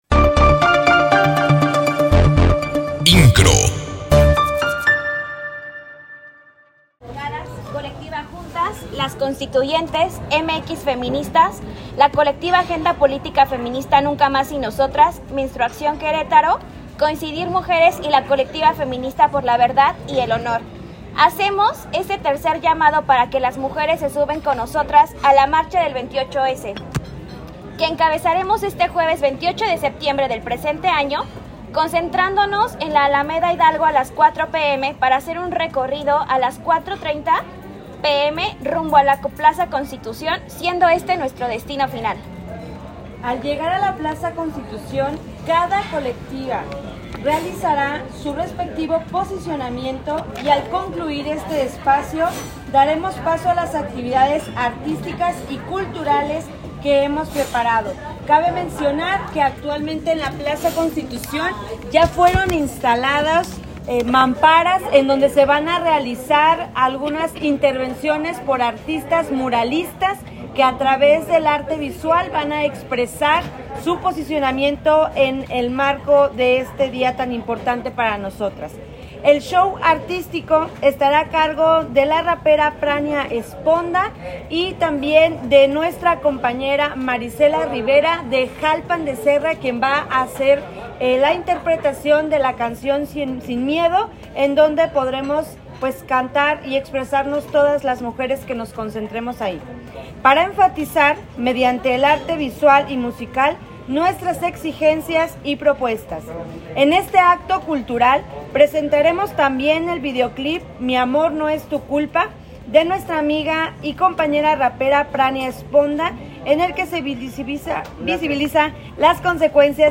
Distintas voces de mujeres se expresaron este día en rueda de prensa para anunciar una marcha que se efectuará este próximo 28 de septiembre a partir de las 4 de la tarde.